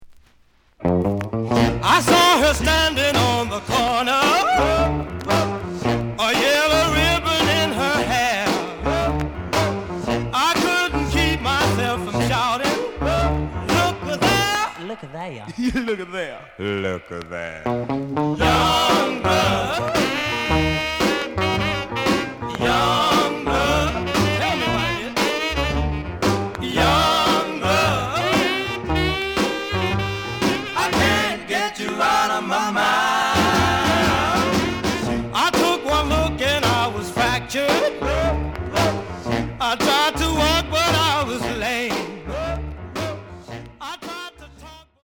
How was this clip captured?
The audio sample is recorded from the actual item. Some click noise on both sides due to scratches.)